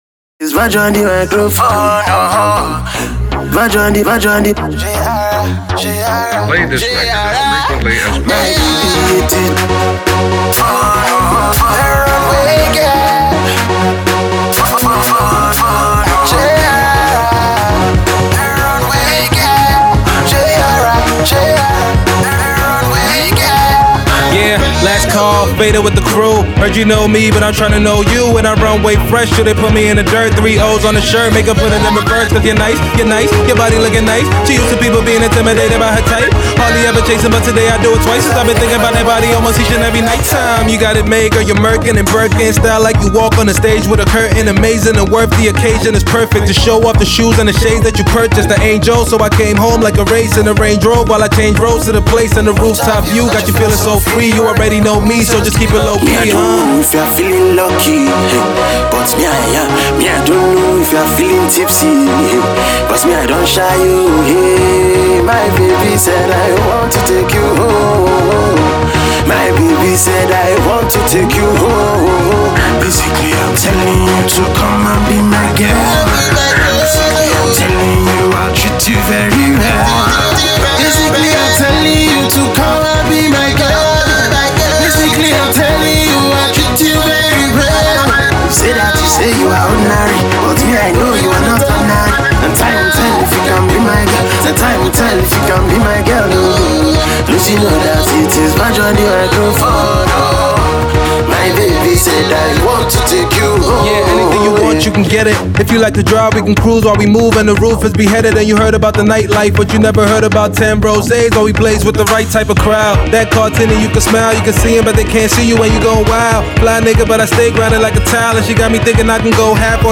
flosses his rap skills
croons in his peculiar husky tone